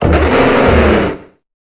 Cri de Bétochef dans Pokémon Épée et Bouclier.